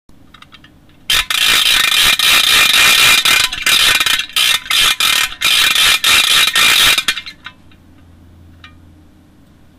Halloween noisemakers